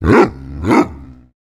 Minecraft Version Minecraft Version latest Latest Release | Latest Snapshot latest / assets / minecraft / sounds / mob / wolf / big / bark1.ogg Compare With Compare With Latest Release | Latest Snapshot
bark1.ogg